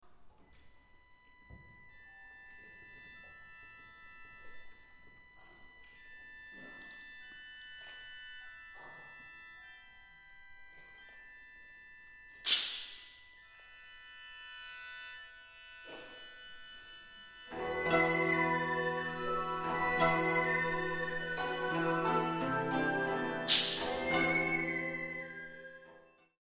邦楽器による伝説舞台
合奏